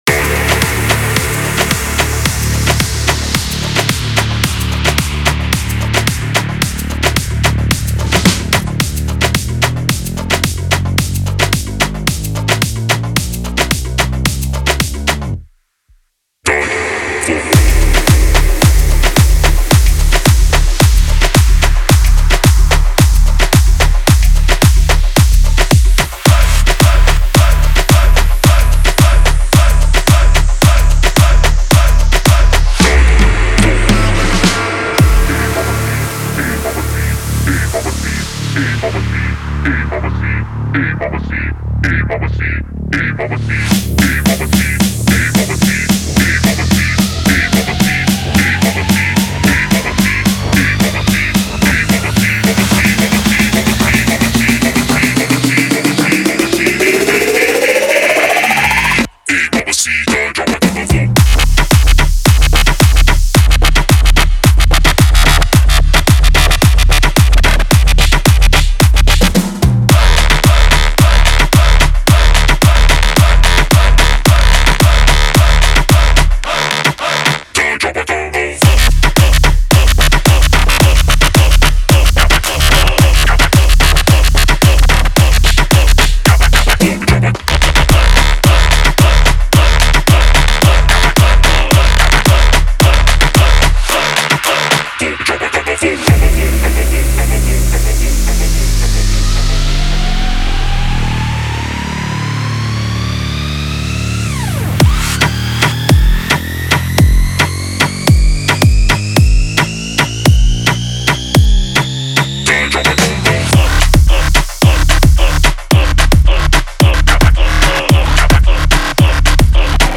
это мощный трек в жанре альтернативного попа